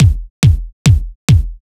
Techno / Drum / KICK017_TEKNO_140_X_SC2.wav